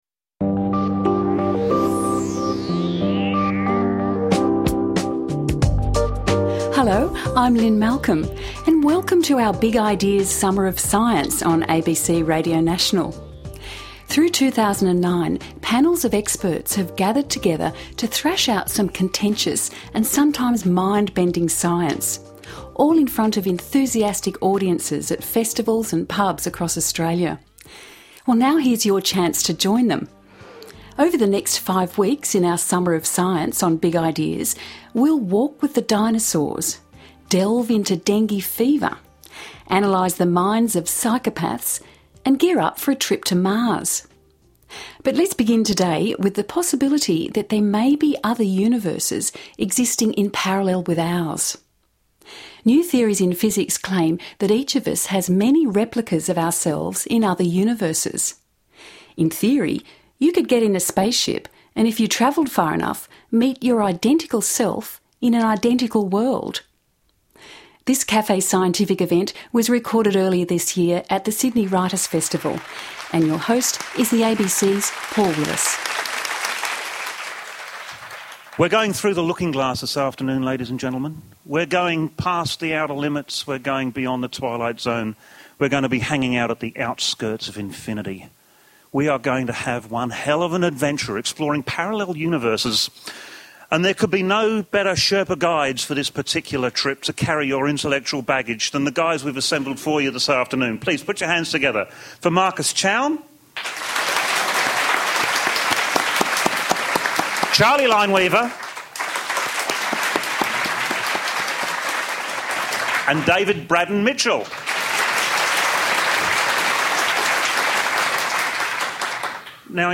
Cafe Scientifique, Sydney Writer's Festivel
with guests